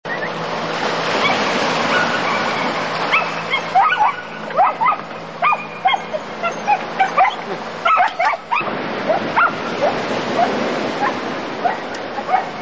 les vagues sur la plage de l'île du Horn ?
Une plage de très gros galets il est possible de débarquer par beau temps ( demander l'avis des militaires chiliens qui gardent l'île ) .
Vous entendrez aussi le chien des militaires .
Authentique !!! , enregistré le 12 avril 1994